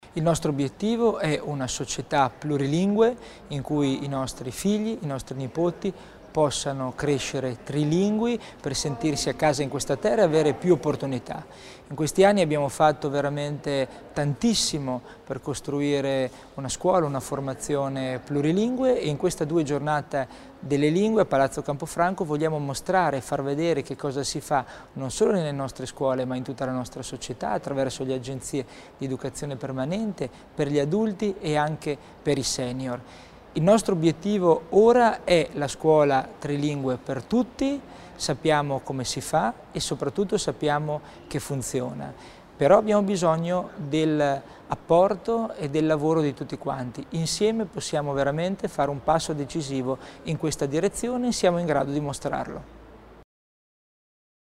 L'Assessore Tommasini spiega l'importanza del Festival delle Lingue